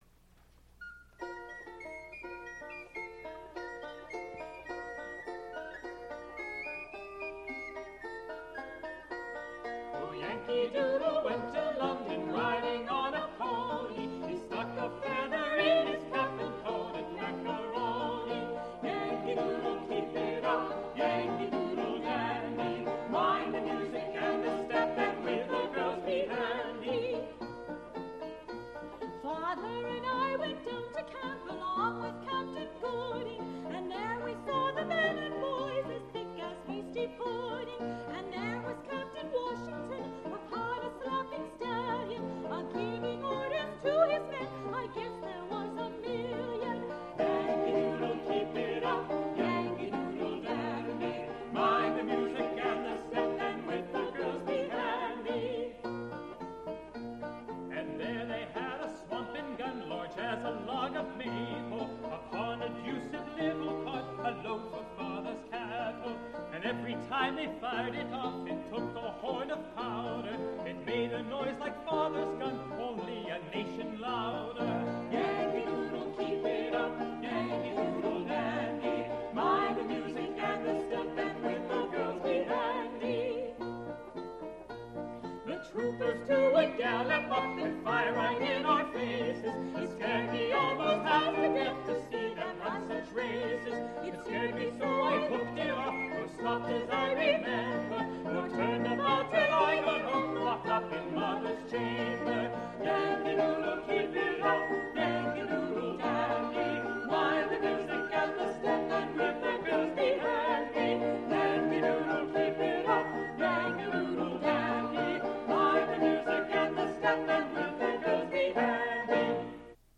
Spoken intro for Yankee Doodle